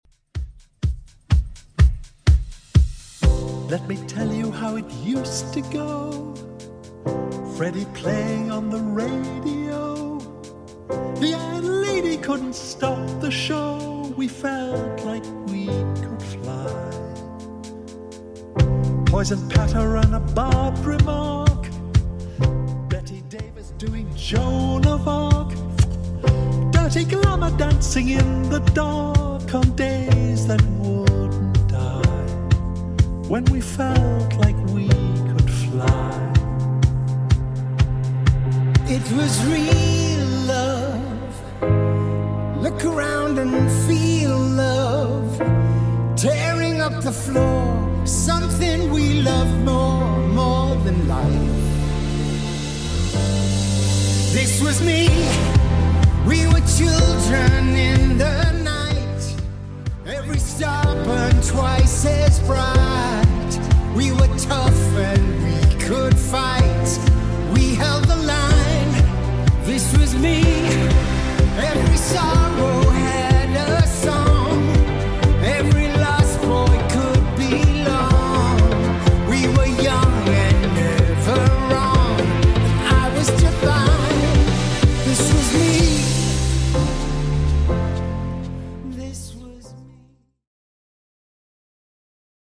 Genre: 80's
BPM: 121